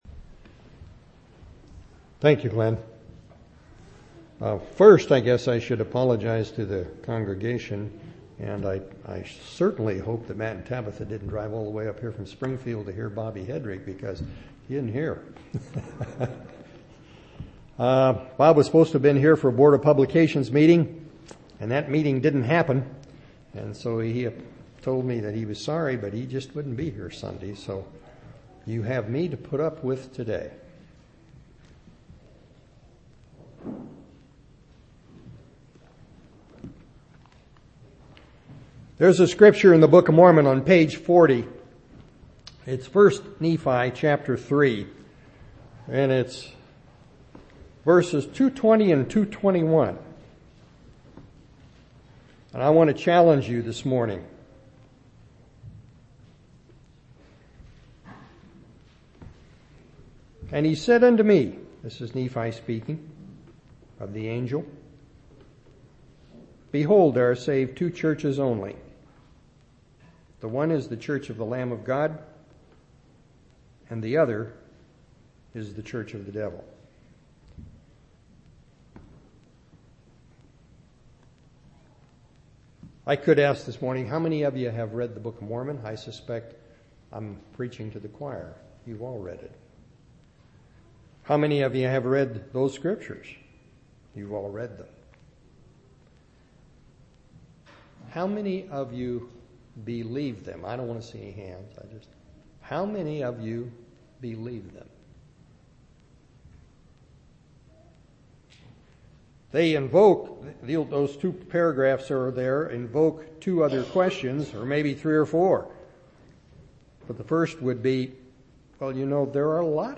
11/14/2004 Location: Temple Lot Local Event